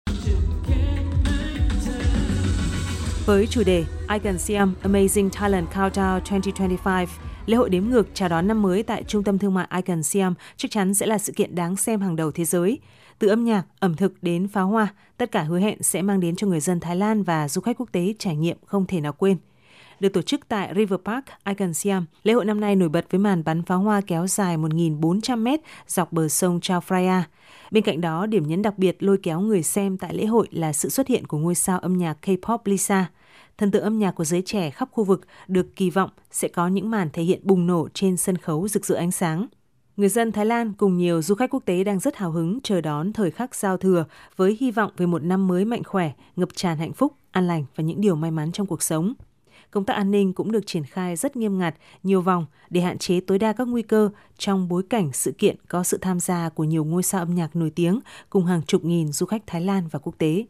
VOV1 - Năm mới 2025 đã gõ cửa Thái Lan, đêm qua hàng chục nghìn người dân Thái Lan và du khách quốc tế đang có mặt tại Trung tâm thương mại Icon Siam đã cùng hòa mình vào bầu không khí lễ hội đếm ngược ngập tràn sắc màu của âm nhạc, ánh sáng và những màn pháo hoa mãn nhãn.